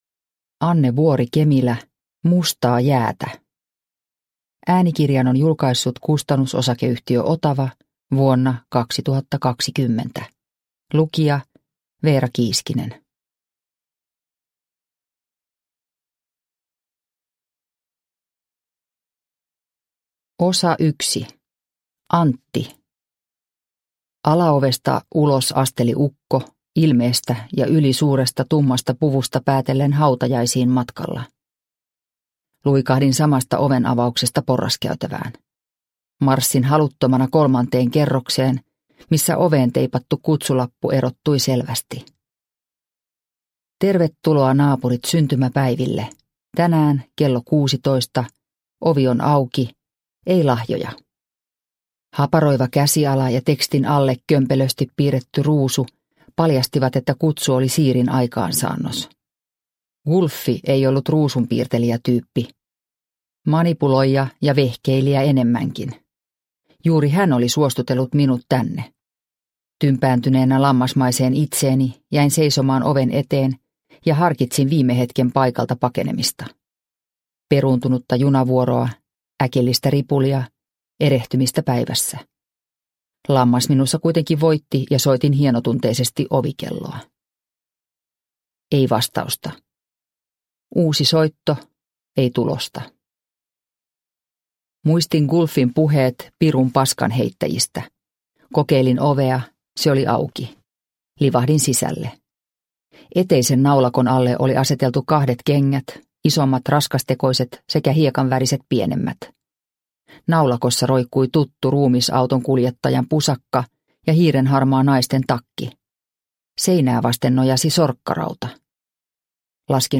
Mustaa jäätä – Ljudbok – Laddas ner